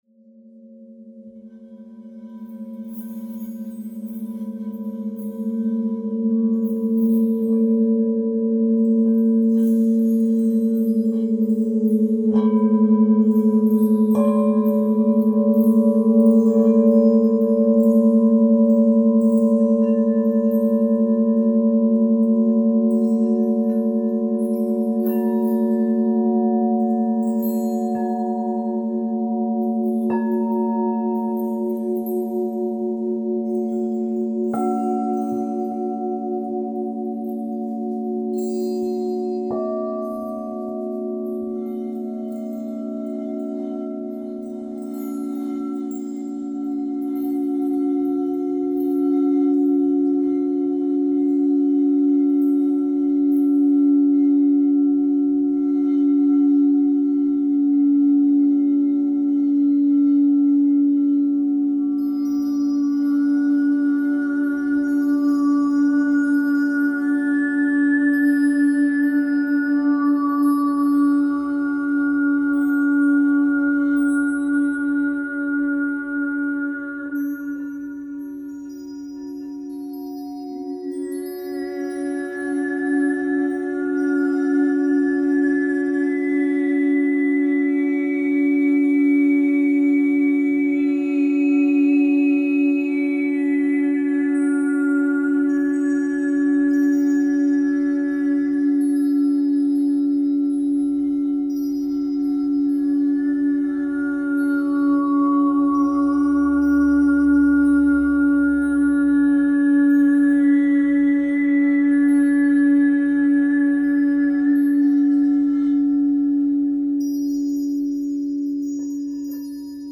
オリエンタルでミステリアスなスピリチュアル瞑想系サウンド